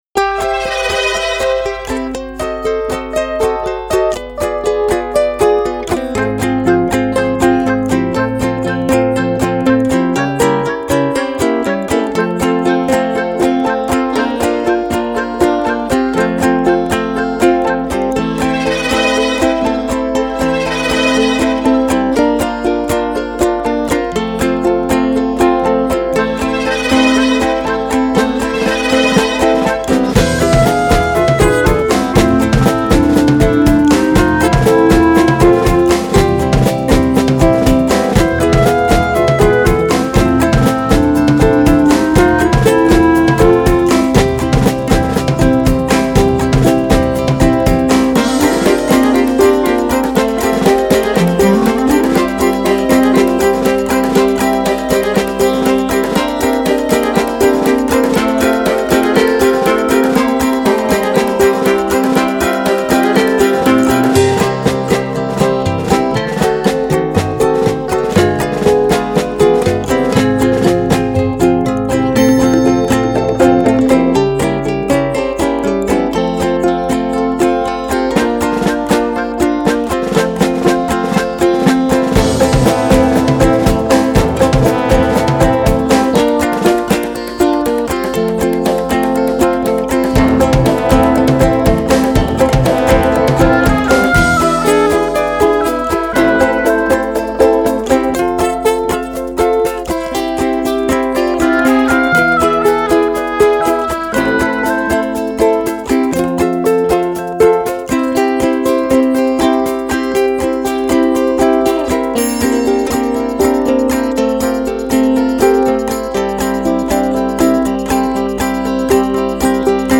genial, lilting, big-hearted pop songs
jaunty instrumental ditty